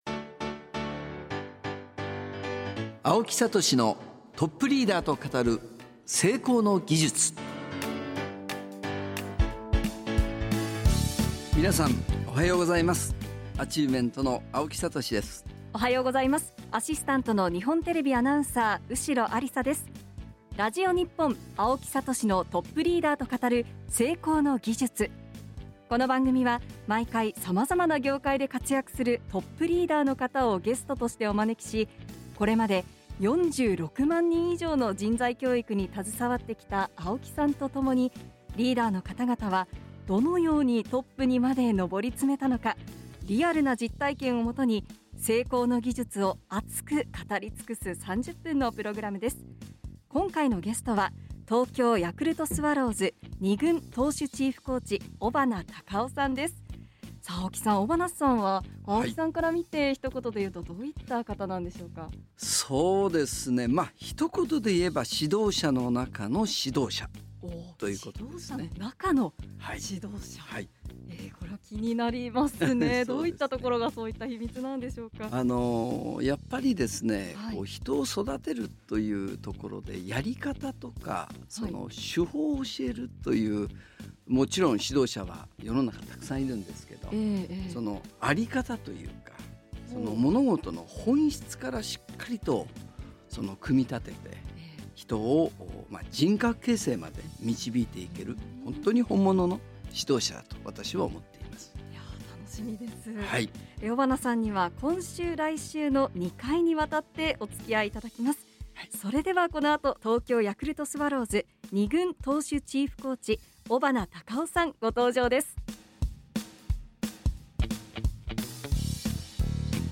今週のゲストは 東京ヤクルトスワローズ 投手チーフコーチの 尾花髙夫さんです。